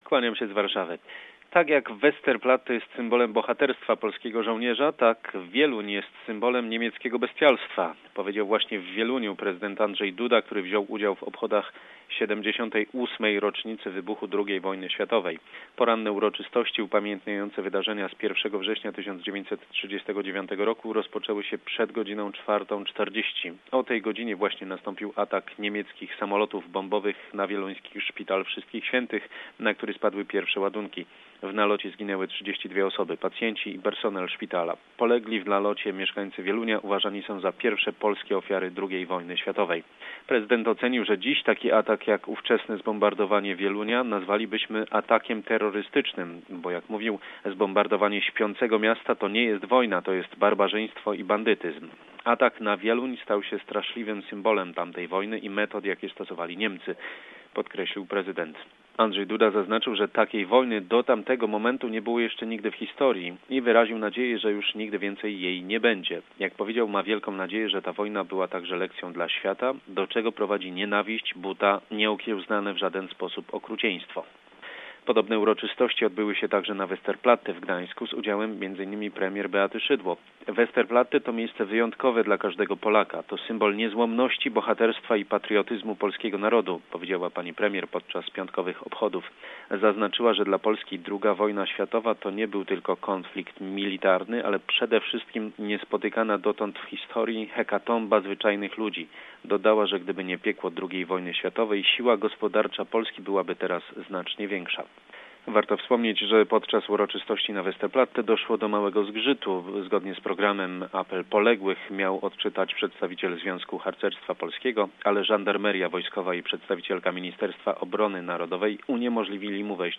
in his weekly report